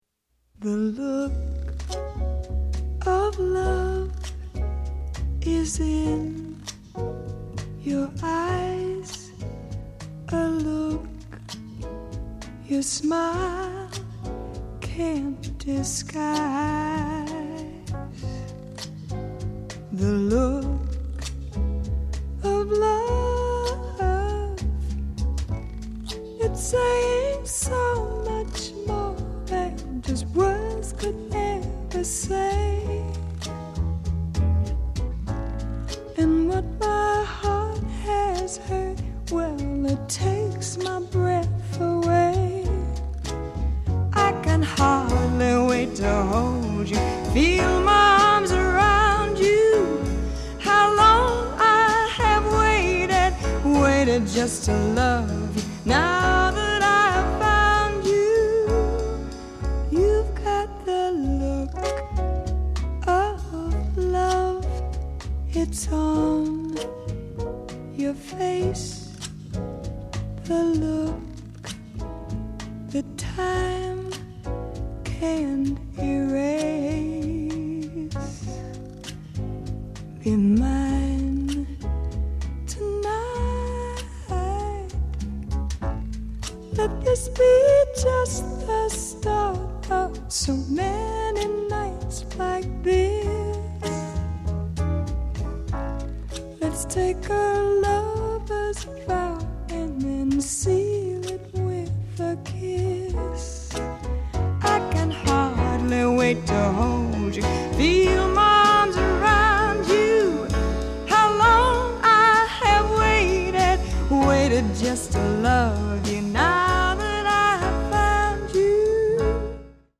★ 以Hi-Res格式製作母帶重現巨星的委婉動人歌聲！